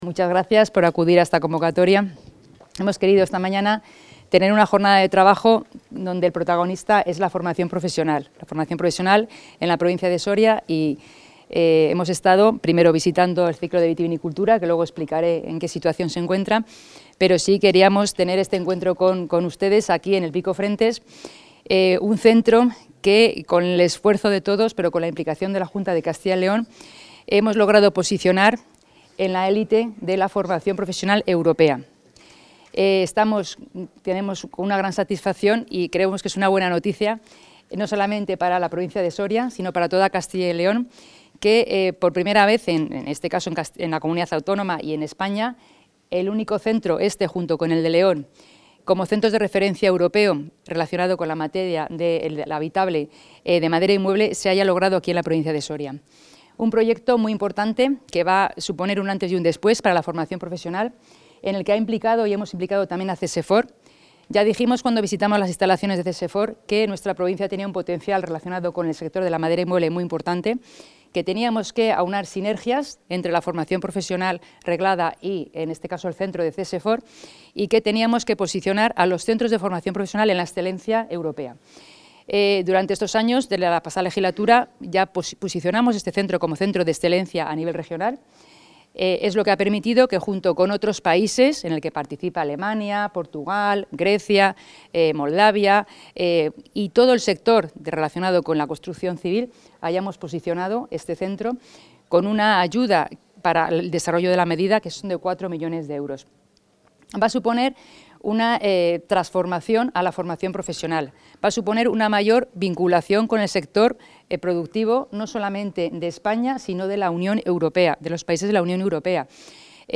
Declaraciones de la consejera.
Castilla y León es la primera comunidad autónoma que consigue que centros públicos de Formación Profesional alcancen el reconocimiento de centros europeos de excelencia profesional y se conviertan en referentes de la red europea. Así lo ha recordado la consejera de Educación, Rocío Lucas, durante su visita al Centro Integrado de Formación Profesional (CIFP) ‘Pico Frentes’ de Soria.